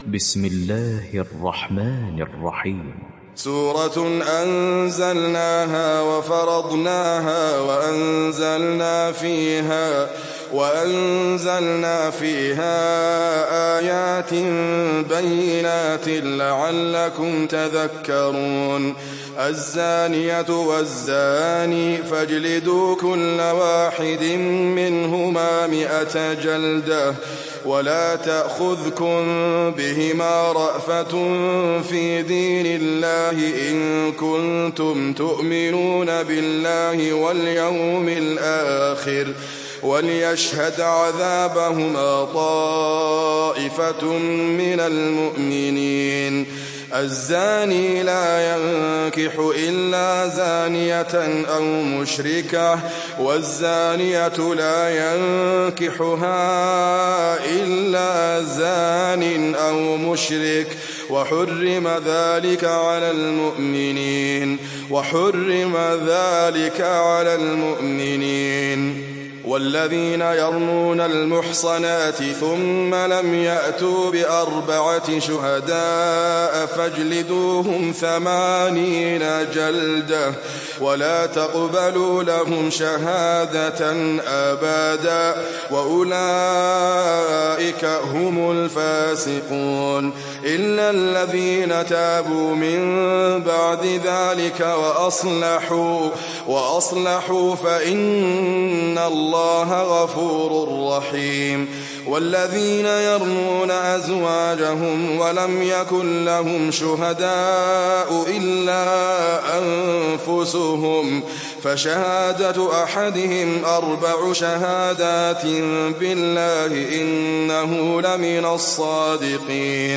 دانلود سوره النور mp3 إدريس أبكر روایت حفص از عاصم, قرآن را دانلود کنید و گوش کن mp3 ، لینک مستقیم کامل